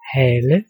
Ääntäminen
IPA: /haːlə/ IPA: [ˈhæːlə]